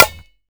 SNARE.104.NEPT.wav